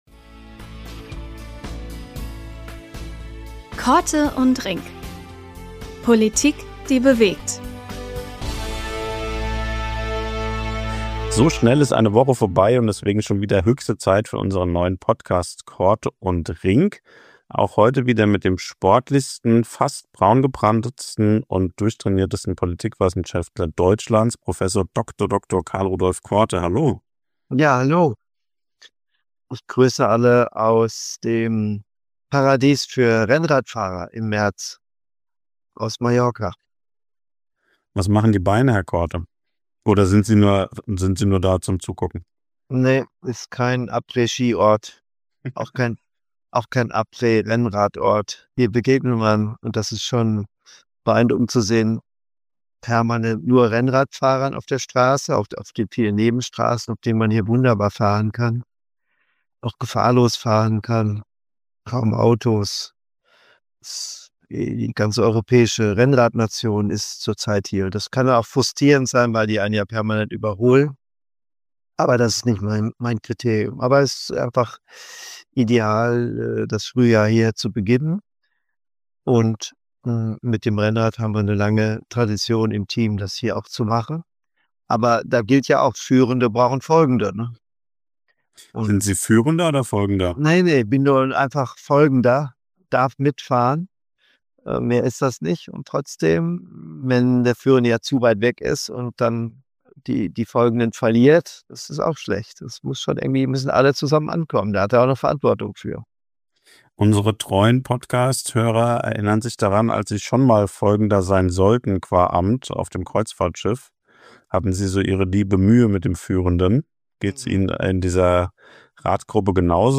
Beschreibung vor 2 Wochen Prof. Dr. Karl-Rudolf Korte meldet sich diesmal aus Mallorca – dort ist er mit Freunden auf dem Rennrad unterwegs und erklimmt die Berge der Insel.